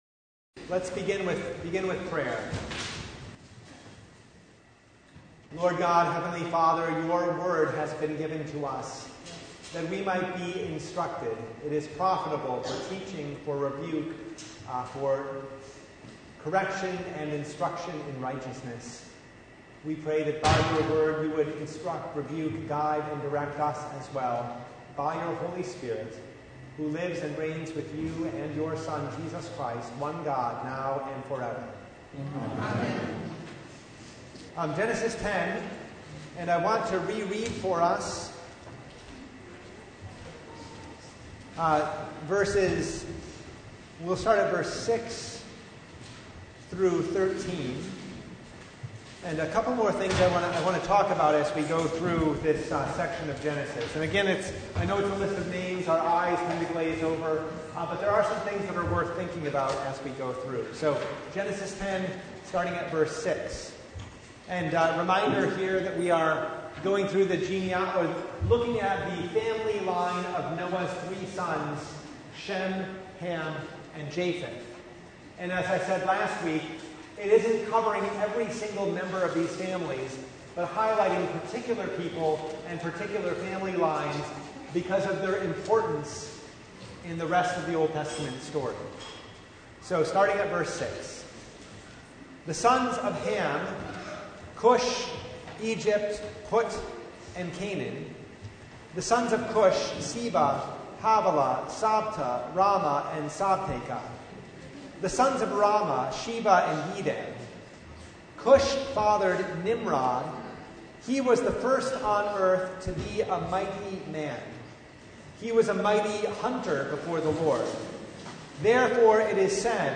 Bible Study « Work and Rest The Second Sunday after Holy Trinity